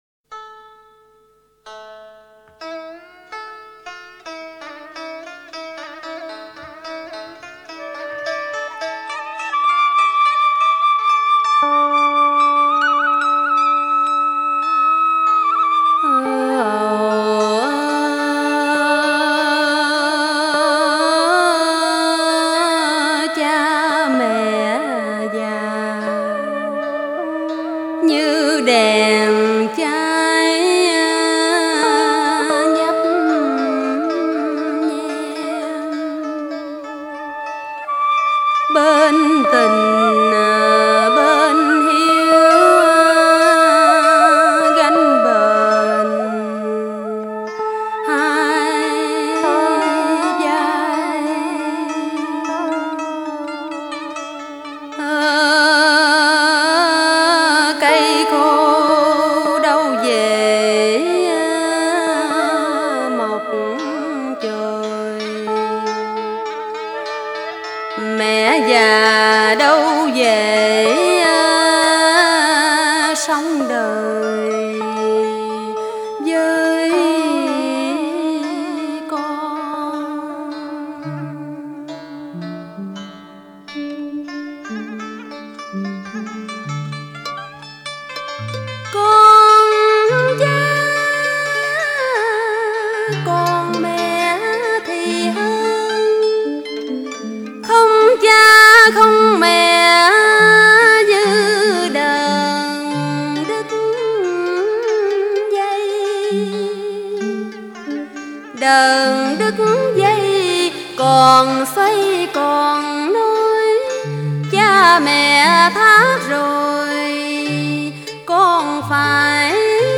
22.1. Hát ru Nam Bộ.mp3